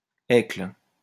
Escles (French pronunciation: [ɛkl]